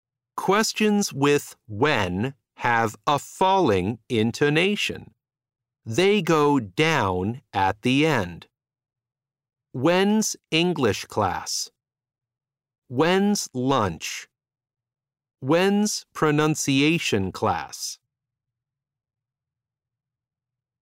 SOB- Unit 2- Lesson 4 (Intonation).mp3